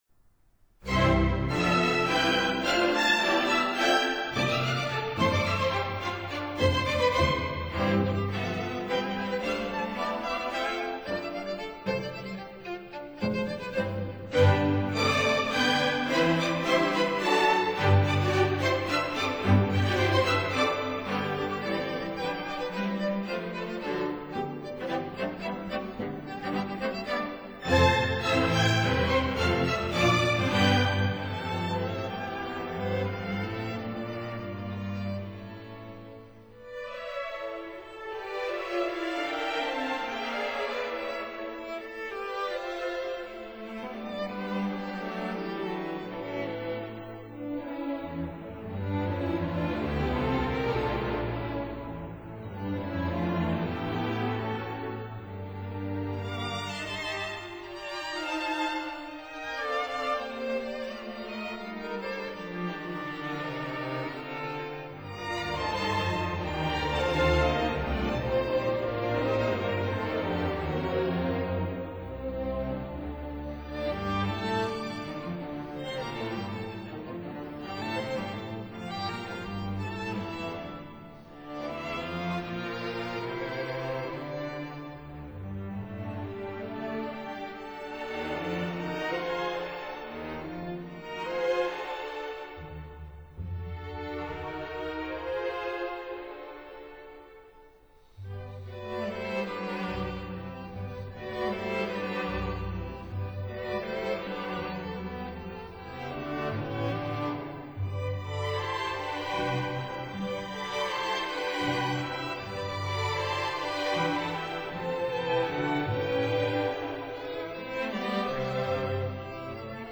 for String Orchestra